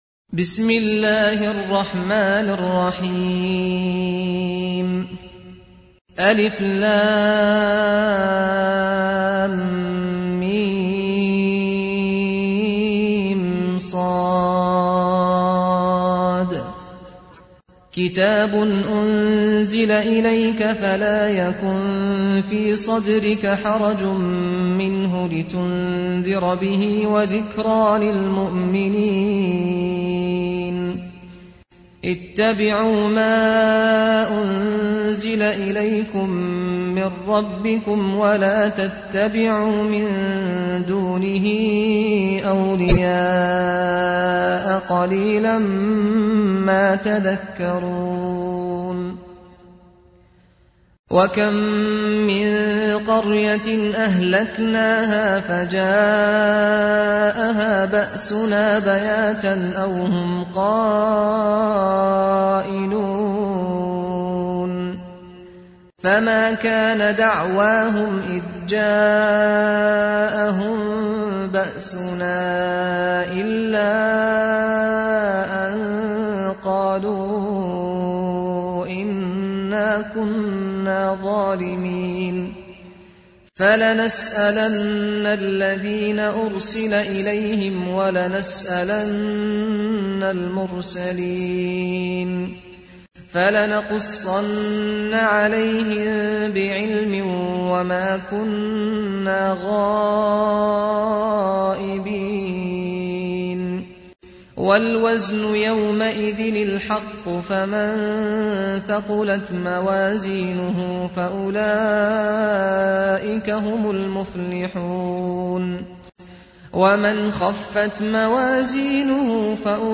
تحميل : 7. سورة الأعراف / القارئ سعد الغامدي / القرآن الكريم / موقع يا حسين